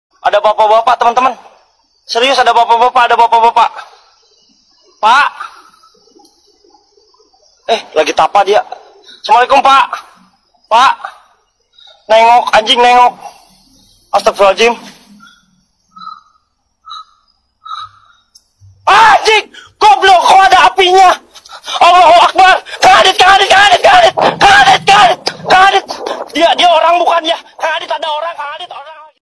Kategori: Suara viral